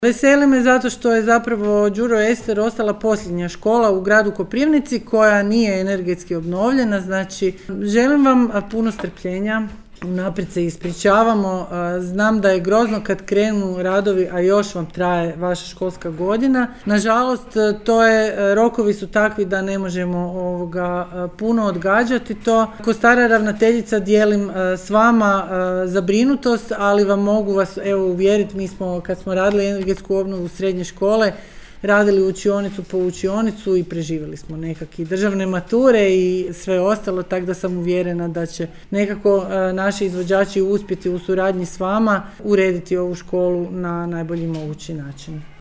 U Osnovnoj školi Đuro Ester u Koprivnici održana je početna konferencija kojom je i službeno najavljen početak projekta energetske obnove škole. Vrijednost projekta iznosi 2,2 milijuna eura, a njegova realizacija označava završni korak u cjelovitom planu obnove školskih objekata na području grada.
Na samom početku obratila se zamjenica koprivničkog gradonačelnika Ksenija Ostriž;